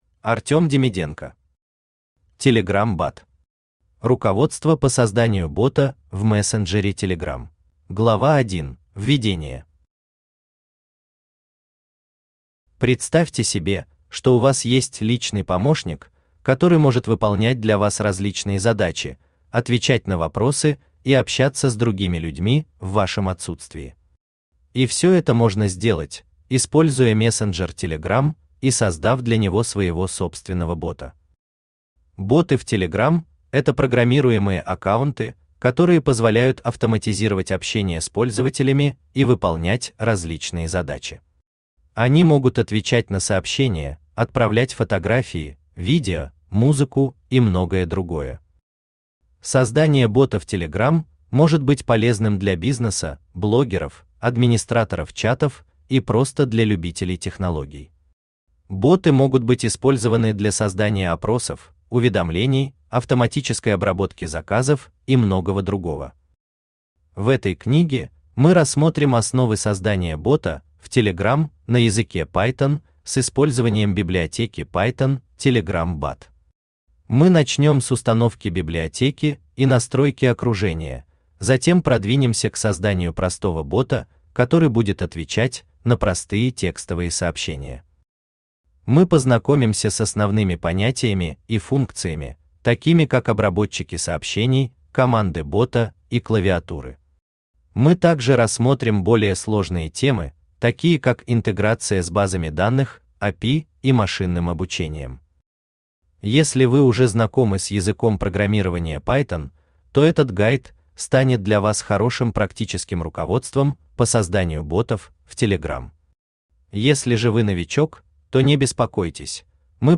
Руководство по созданию бота в мессенджере Телеграм Автор Искусственный Интеллект Читает аудиокнигу Авточтец ЛитРес.